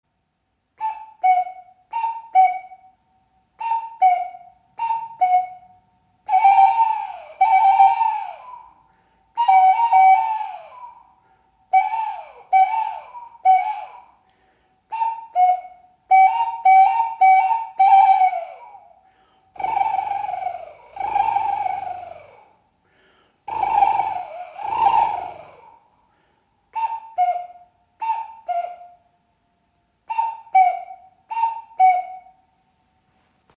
• Icon Täuschend echter Kuckucksruf – Loch einfach auf- und abdecken.
Loch auf- und zuhalten, stossweise blasen – schon erklingt der Kuckucksruf.
Kuckuck-Flöte | Klein